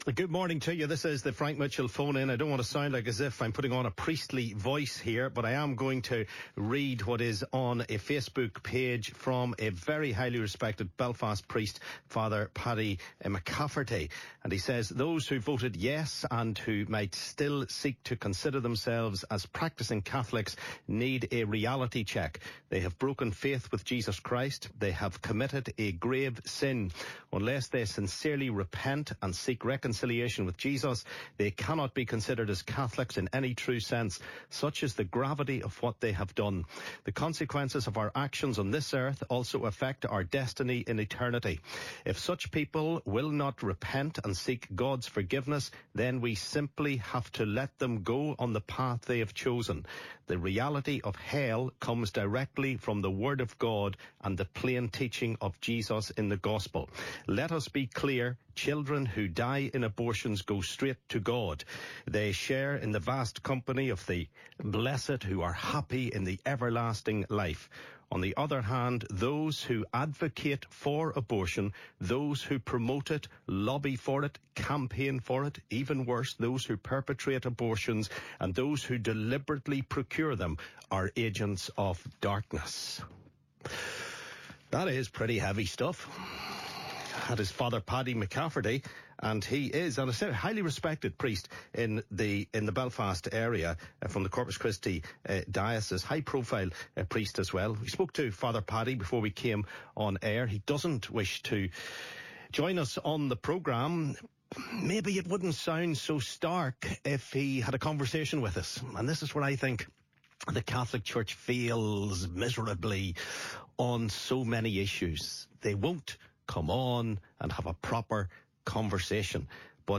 Callers react